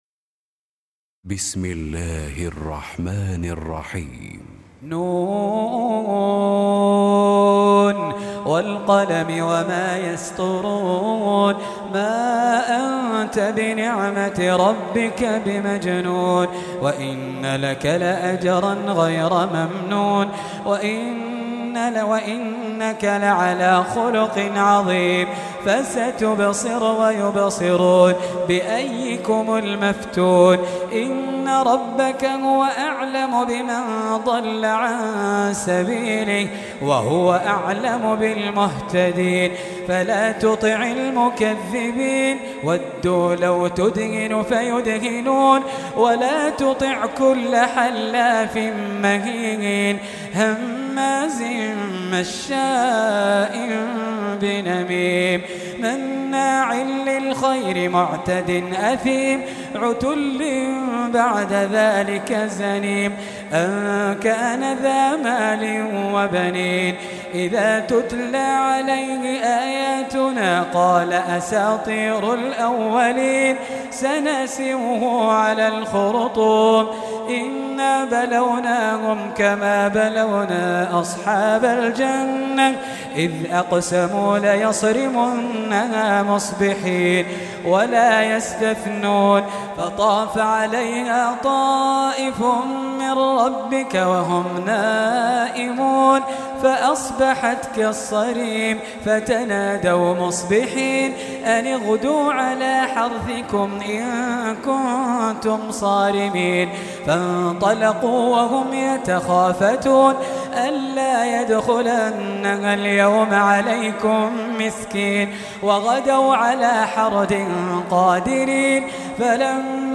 68. Surah Al-Qalam سورة القلم Audio Quran Tarteel Recitation
Surah Sequence تتابع السورة Download Surah حمّل السورة Reciting Murattalah Audio for 68. Surah Al-Qalam سورة القلم N.B *Surah Includes Al-Basmalah Reciters Sequents تتابع التلاوات Reciters Repeats تكرار التلاوات